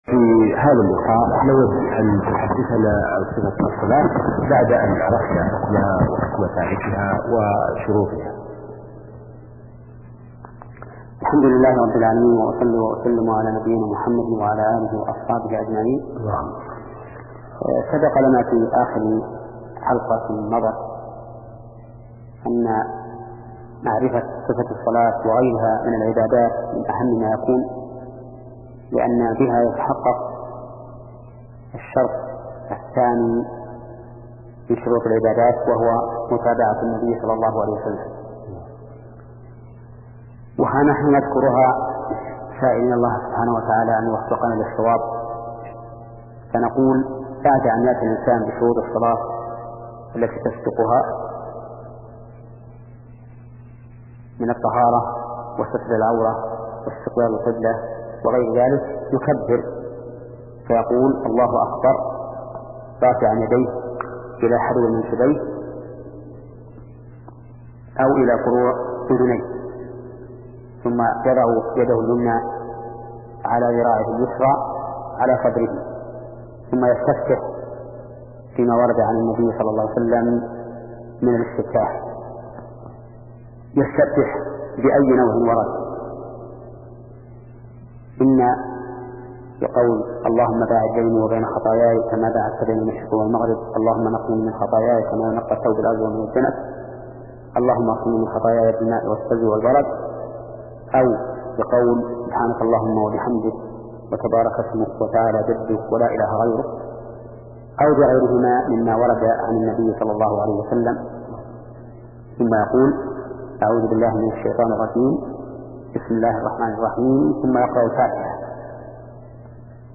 شبكة المعرفة الإسلامية | الدروس | فقه العبادات (27) |محمد بن صالح العثيمين